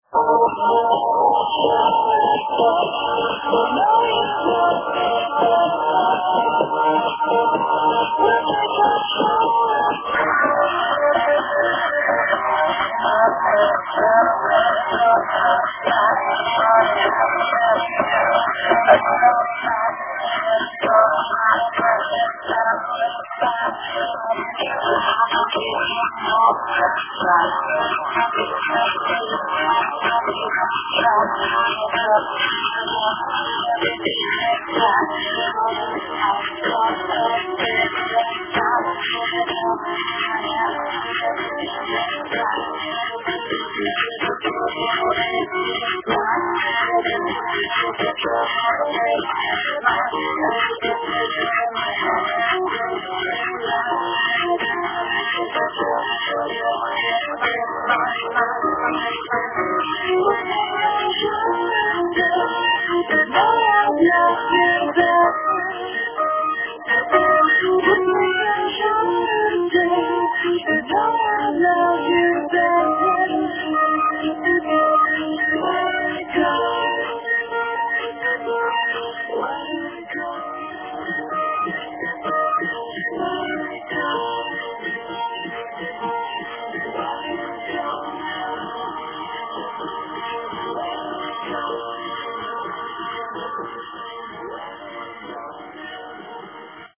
Recorded with a phone, so the quality is quite bad.